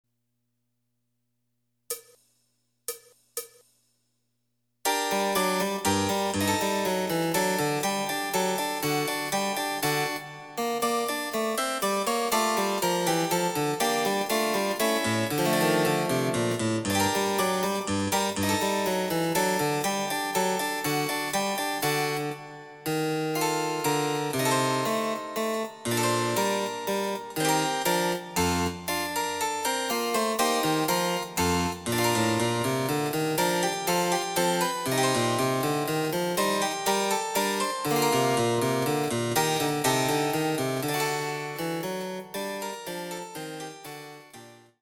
★フルートの名曲をチェンバロ伴奏つきで演奏できる、「チェンバロ伴奏ＣＤつき楽譜」です。
試聴ファイル（伴奏）